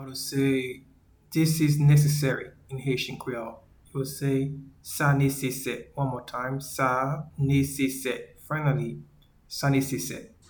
Pronunciation:
This-is-necessary-in-Haitian-Creole-Sa-nesese.mp3